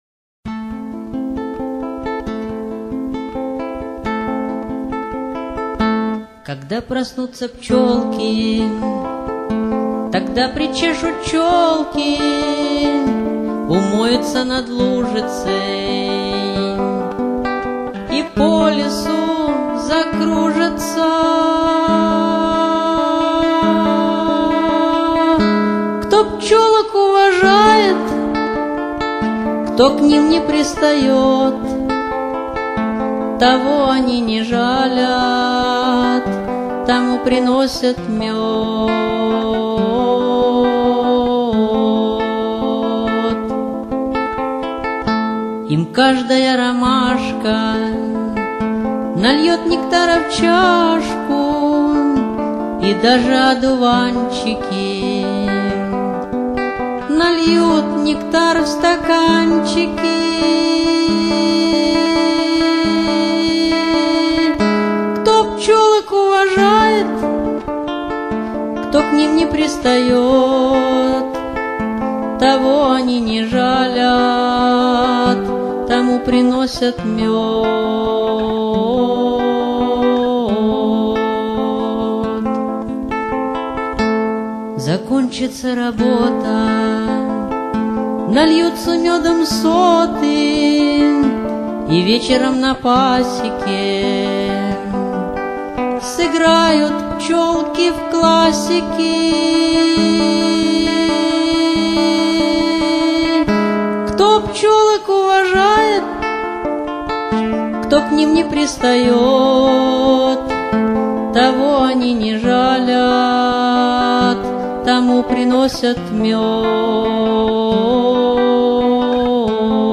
на гитаре